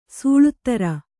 ♪ sūḷuttara